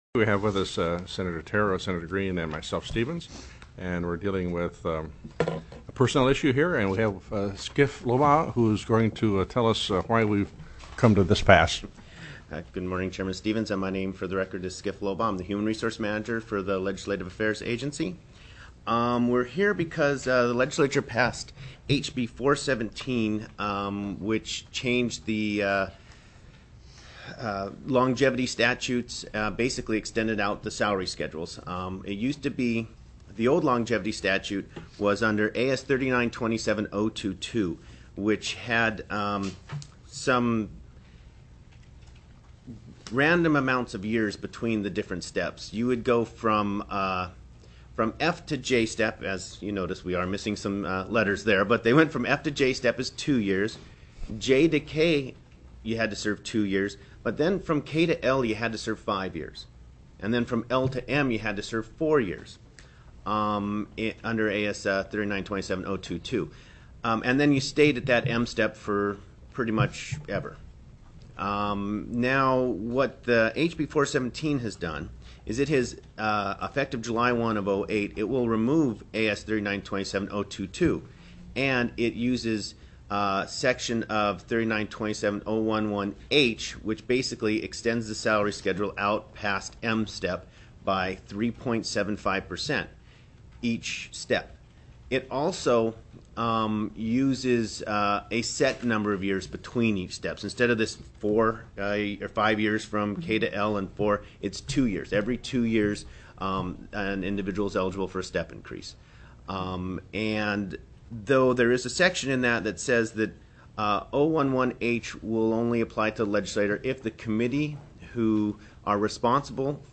SENATE RULES STANDING COMMITTEE
Senator Gary Stevens, Chair